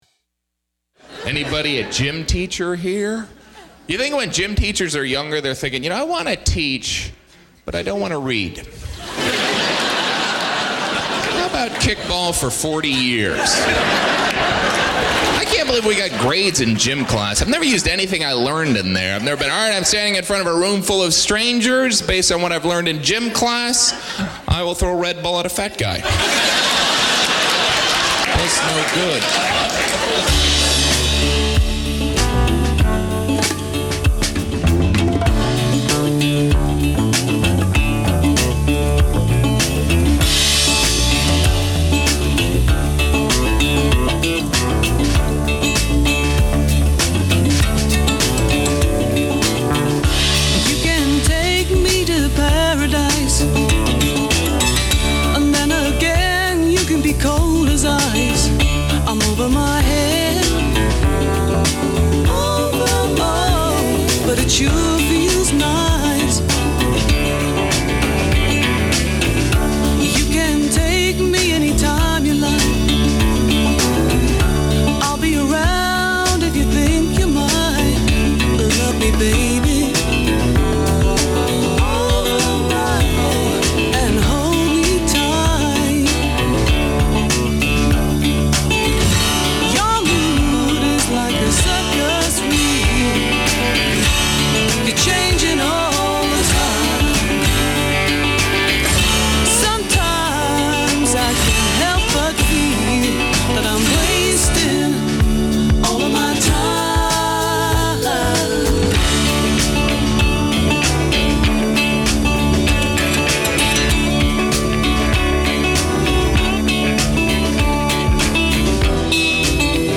joins us in-studio